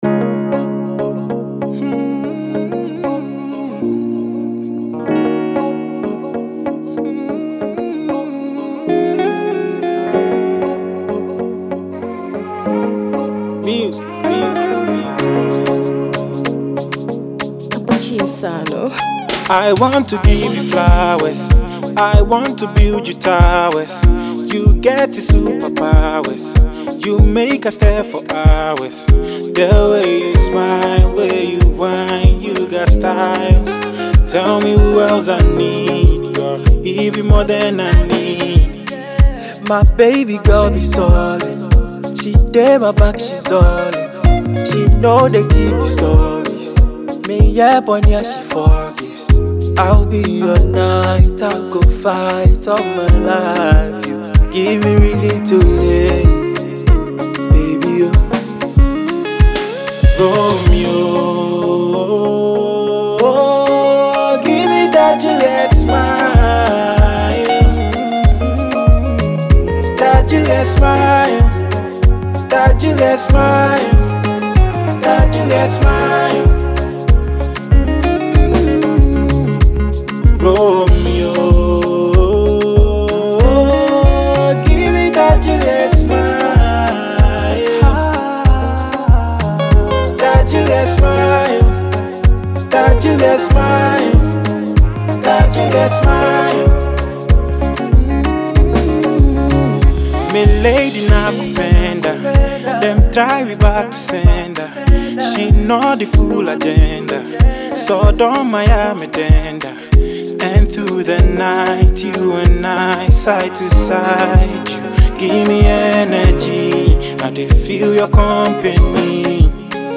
has been featured in yet another soothing piece of music.
an Afro-Fusion joint that thrives on an infectious rhythm
On the Guitar is the legendary Guitarist